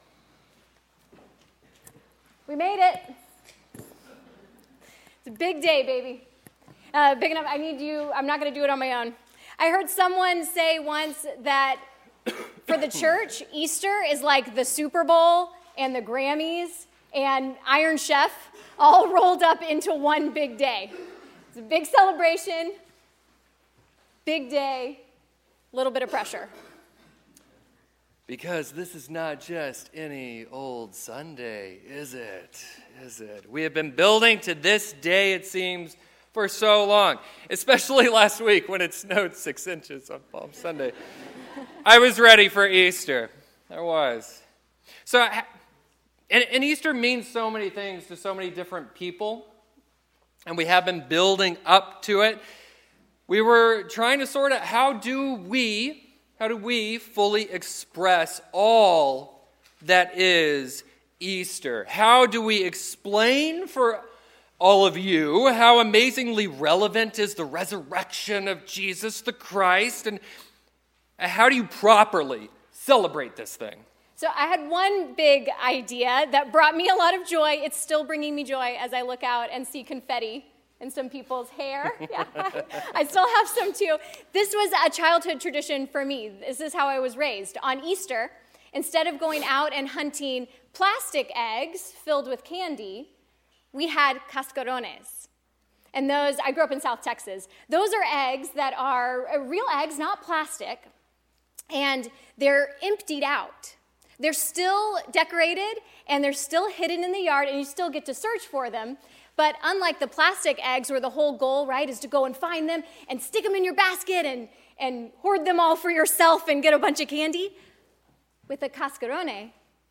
A Sermon for Easter Sunday